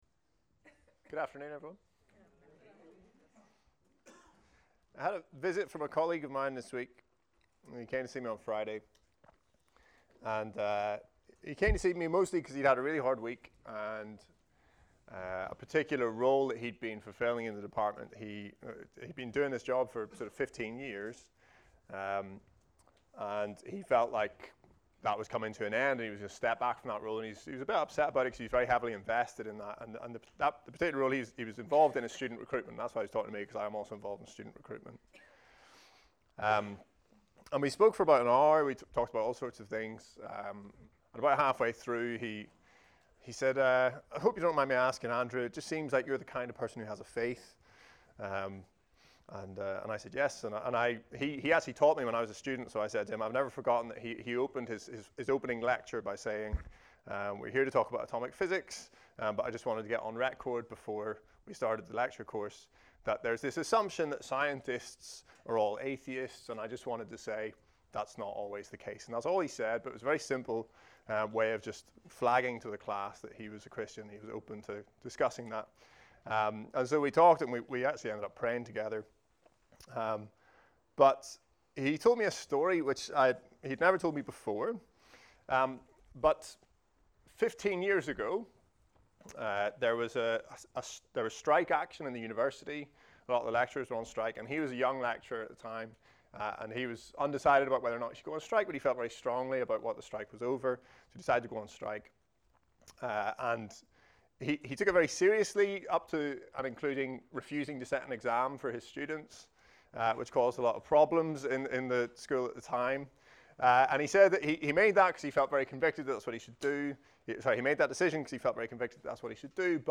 A message from the series "All Messages."